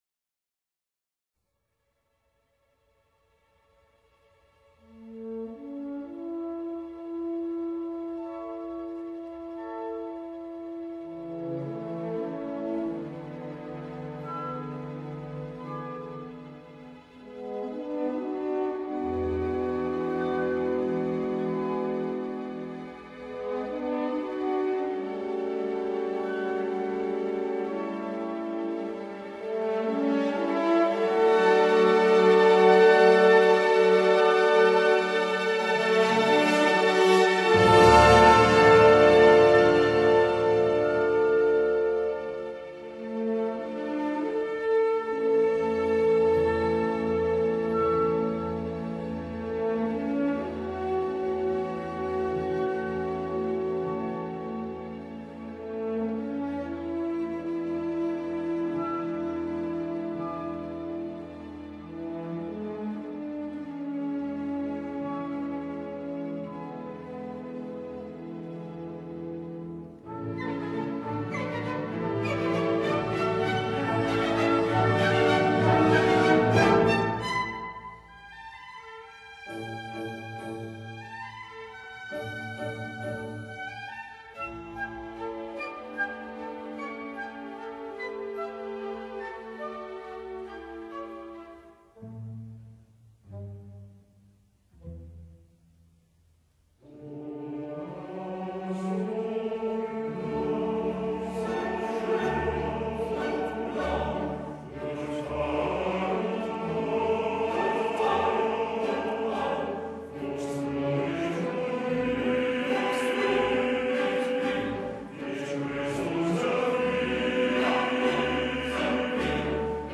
古典类型: 交响曲